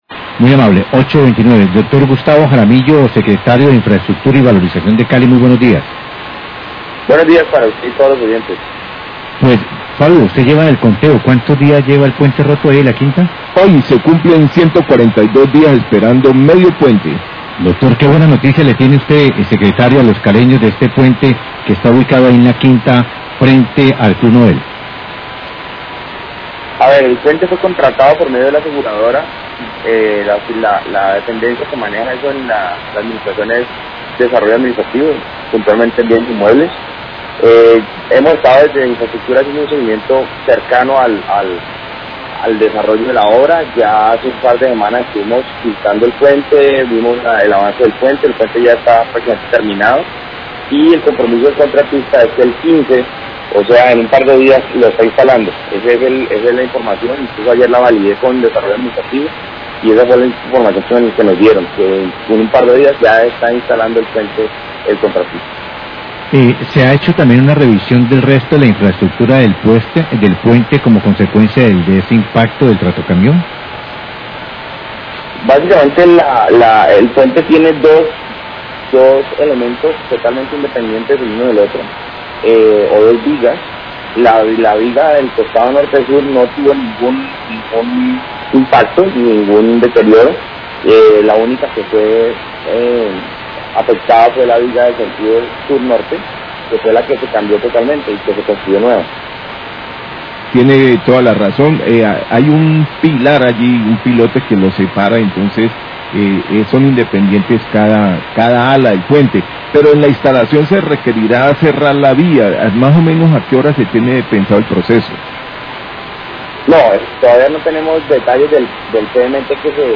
Radio
entrevista